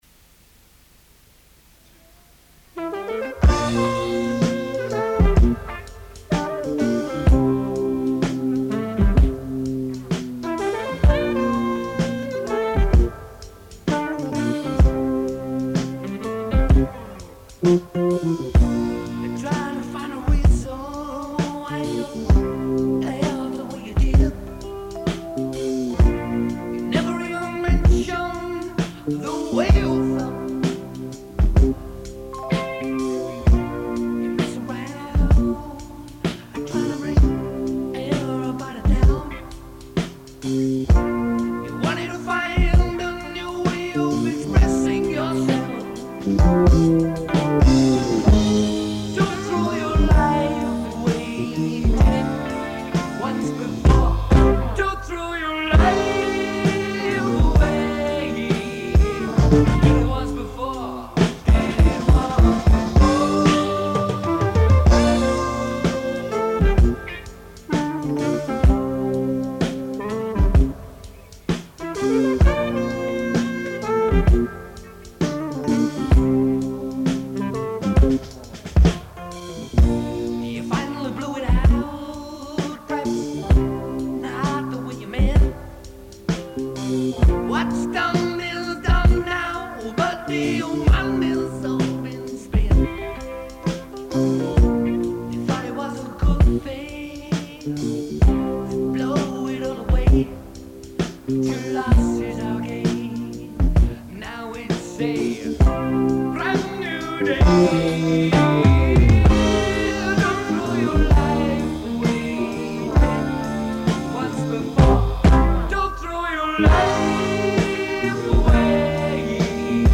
being played live in the studio, no multi-tracking.